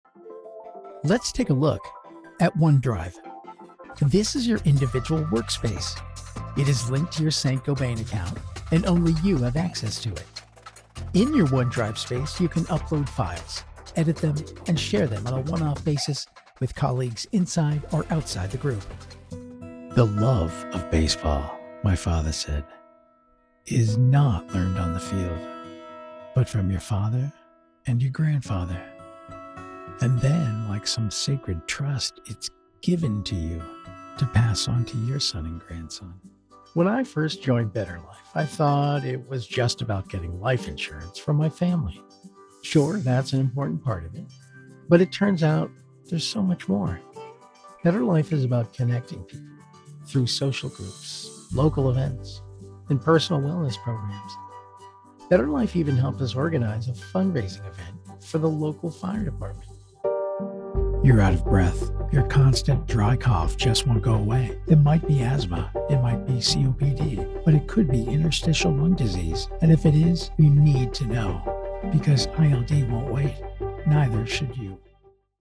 Genuine...relatable...humble.
Demos
English - Midwestern U.S. English
English - New England U.S. English